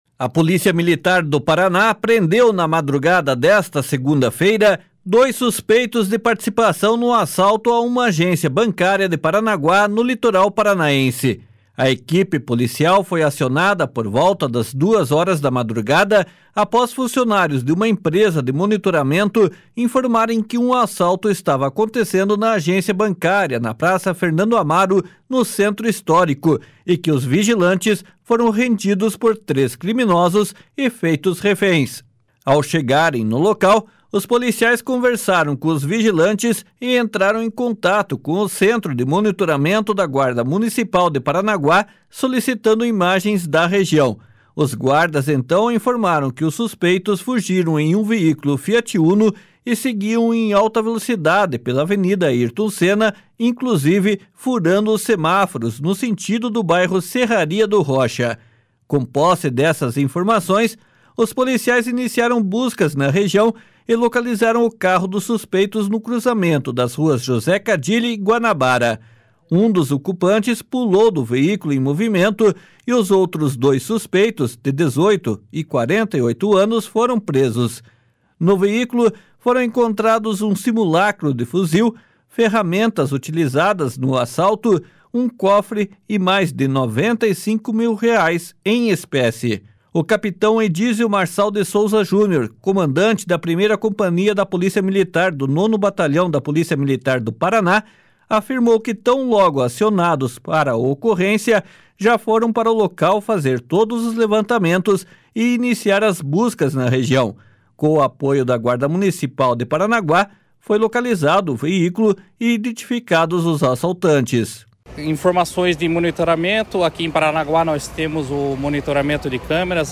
Os suspeitos, os equipamentos e o dinheiro foram encaminhados para a Delegacia da Polícia Federal em Paranaguá para os procedimentos cabíveis. (Repórter